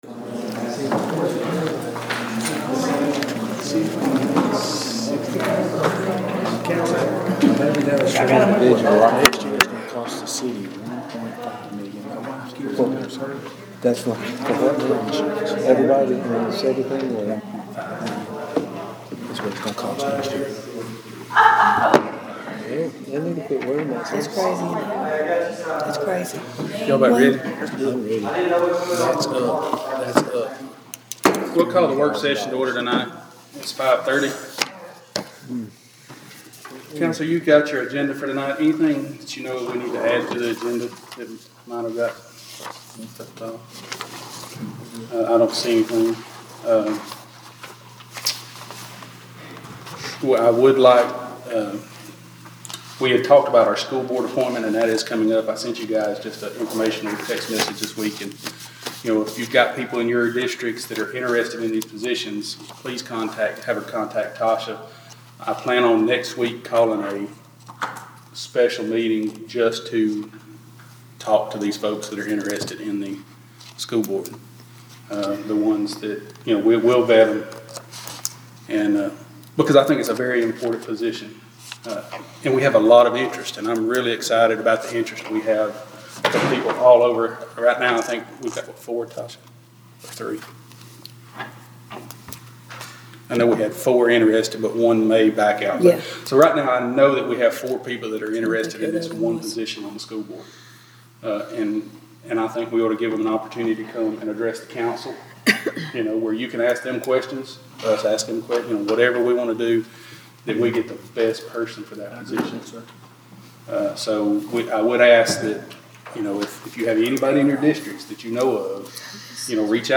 Piedmont Police Chief and Fire Chief Reappointed During Piedmont City Council Meeting During the regular meeting of the Piedmont City Council, action was taken to reappoint Chief Nathan Johnson as Police Chief to continue to run the department.